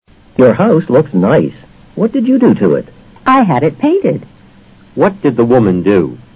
托福听力小对话【95】have the house painted